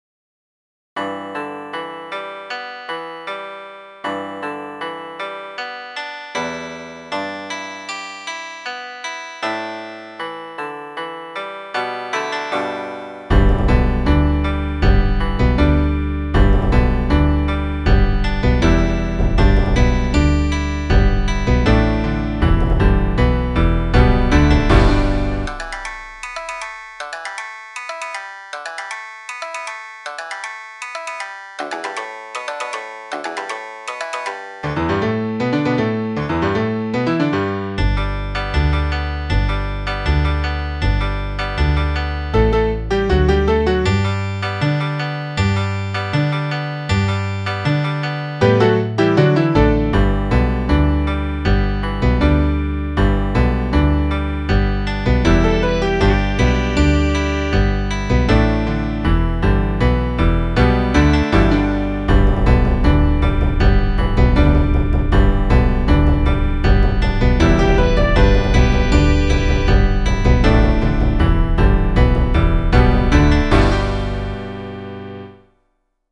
I thought the melody sounded a little like traditional Japanese music, so I used some MIDI instruments like koto, shamisen, and taiko drums.